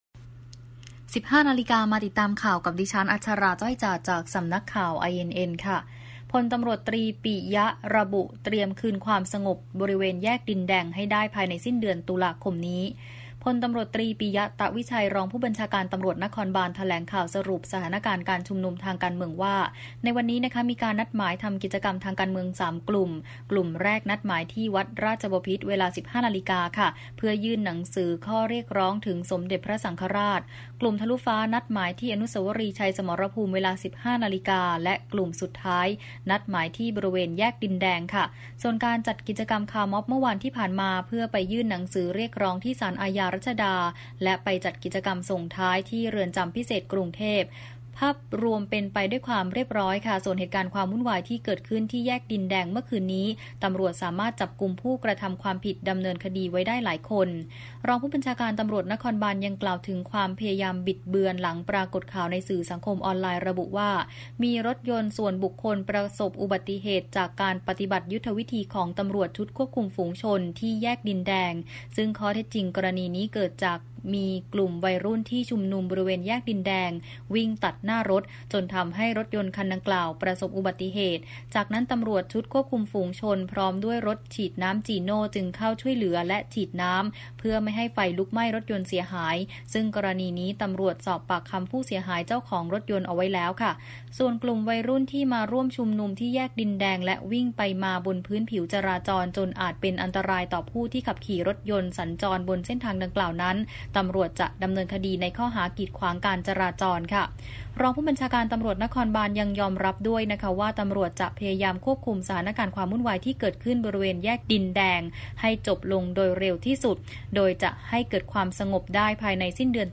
พล.ต.ต.ปิยะ ต๊ะวิชัย รองผู้บัญชาการตำรวจนครบาล แถลงข่าวสรุปสถานการณ์การชุมนุมทางการเมือง ว่า ในวันนี้มีการนัดหมายทำกิจกรรมทางการเมือง 3 กลุ่ม กลุ่มแรกนัดหมาย ที่วัดราชบพิธฯ เวลา 15.00 น. เพื่อยื่นหนังสือข้อเรียกร้องถึงสมเด็จพระสังฆราชฯ, กลุ่มทะลุฟ้า นัดหมายที่อนุสาวรีย์ชัยสมรภูมิ เวลา 15.00 น. และกลุ่มสุดท้าย นัดหมายที่บริเวณแยกดินแดง